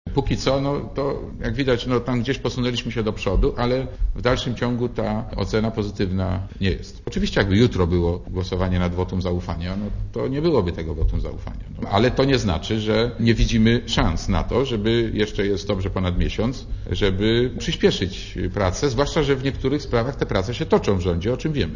* Mówi Marek Borowski*
Na konferencji prasowej w Sejmie - podsumowującej dwa miesiące, jakie upłynęły od decyzji SdPl o poparciu rządu - Borowski powiedział, że gdyby w poniedziałek powtórnie głosowane było wotum zaufania dla rządu, to - biorąc pod uwagę stan realizacji postulatów SdPl - jego partia takiego wotum by nie udzieliła.